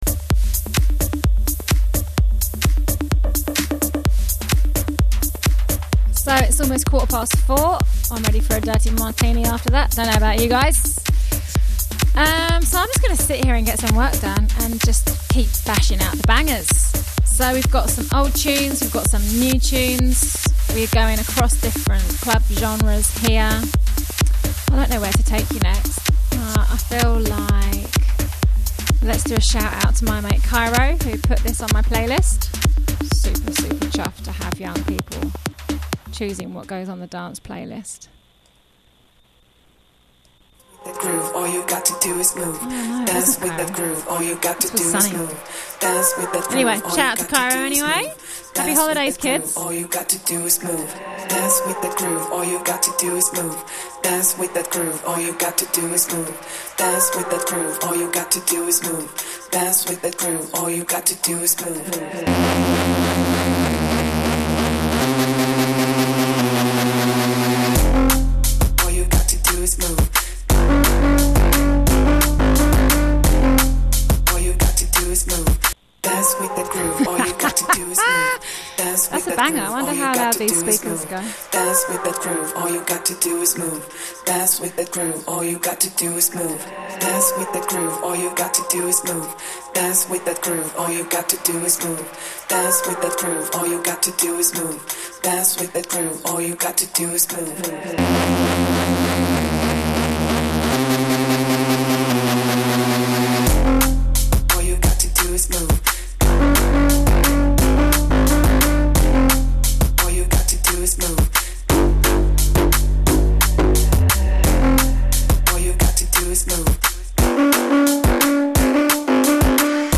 Club mash ups